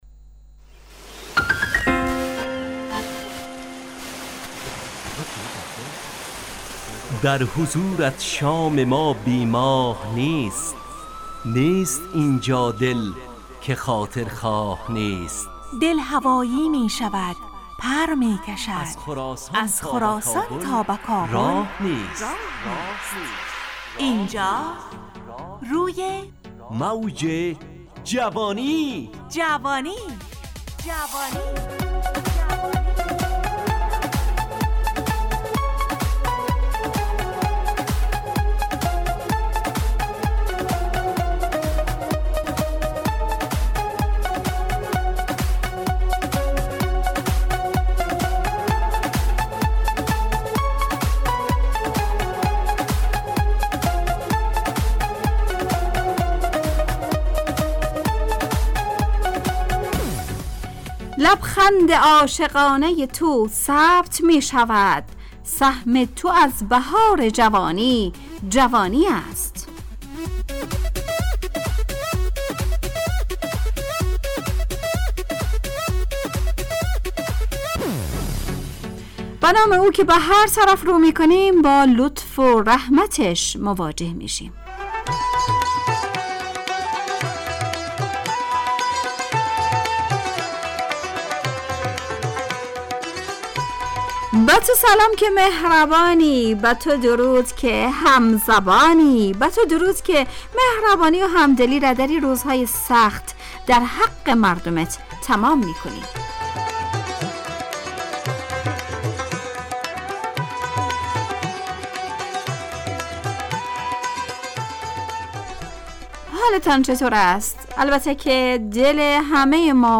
همراه با ترانه و موسیقی مدت برنامه 70 دقیقه . بحث محوری این هفته (نقش) تهیه کننده